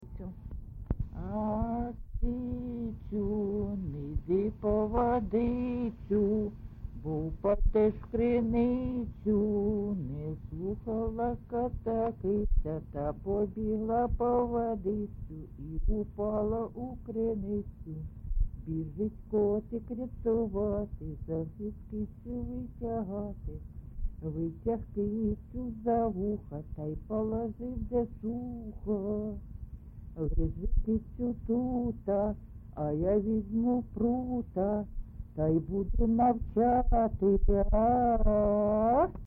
GenreLullaby
Recording locationMykhailivka, Shakhtarskyi (Horlivskyi) district, Donetsk obl., Ukraine, Sloboda Ukraine